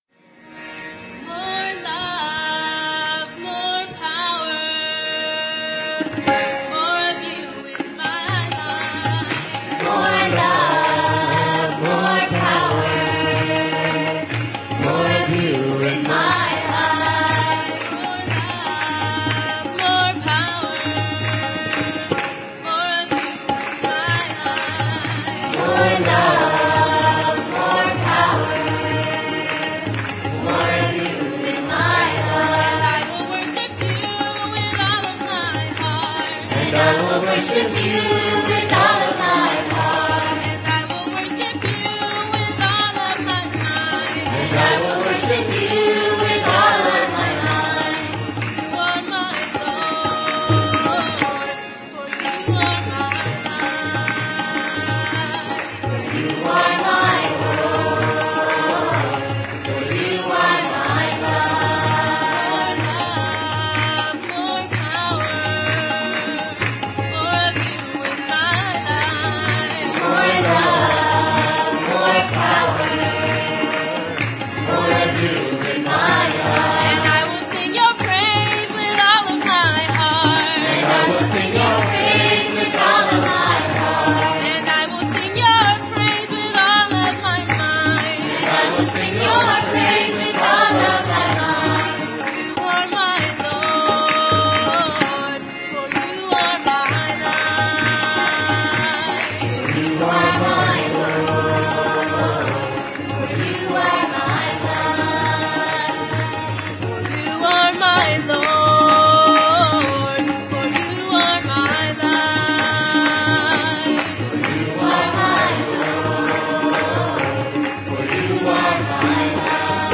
Minor (Natabhairavi)
8 Beat / Keherwa / Adi
Fast
4 Pancham / F